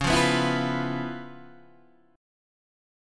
C#7#9 chord {9 8 9 9 6 7} chord